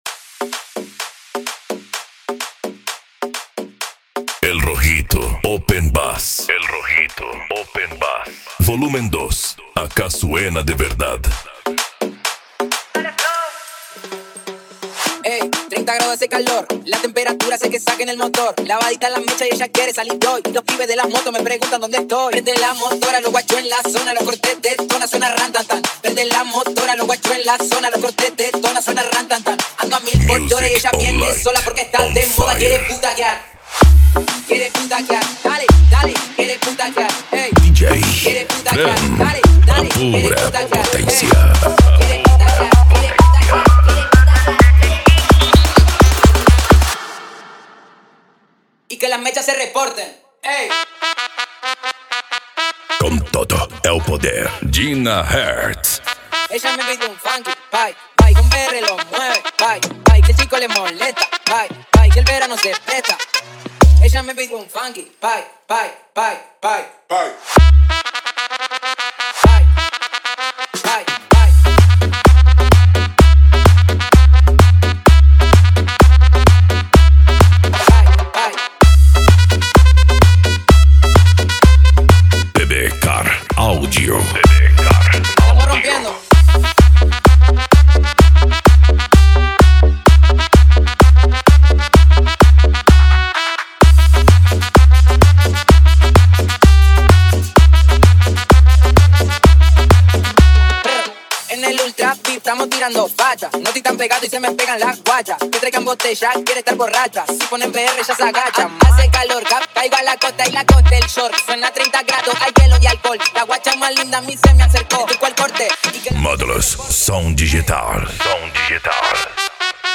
Electro House
Psy Trance
Remix